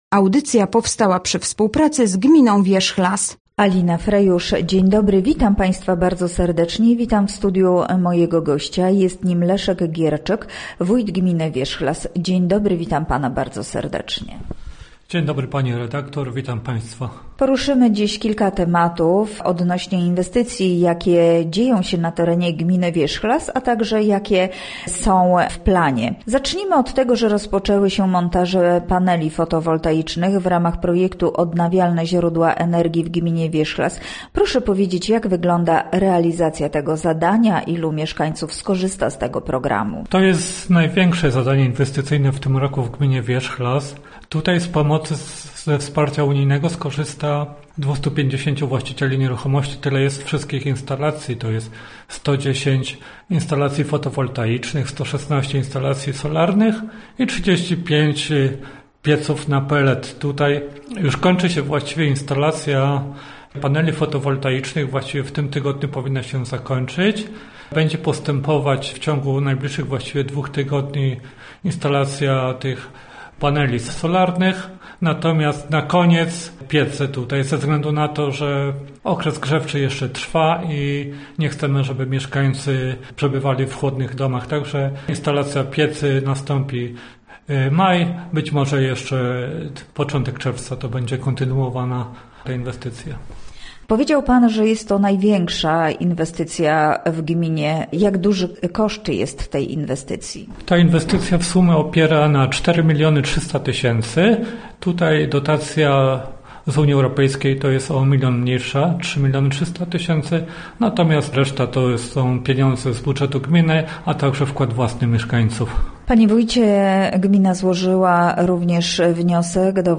Gościem Radia ZW był Leszek Gierczyk, wójt gminy Wierzchlas